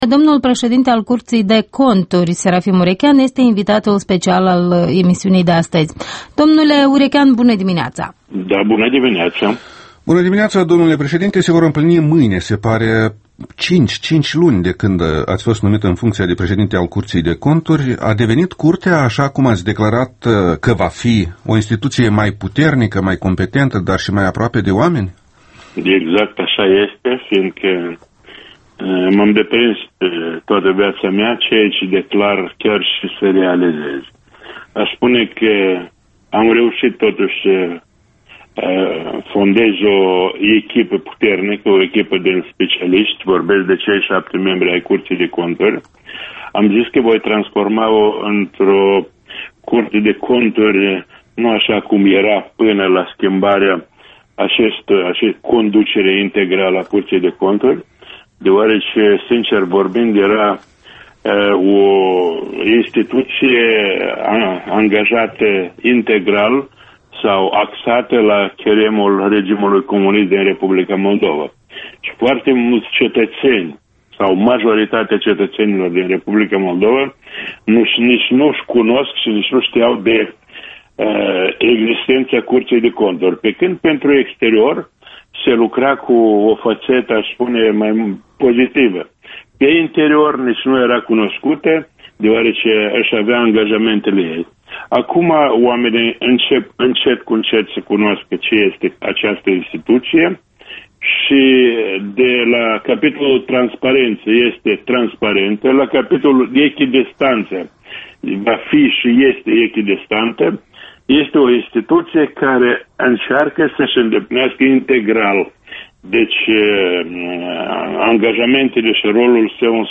Un interviu cu Serafim Urecheanu, președintele Curții de Conturi.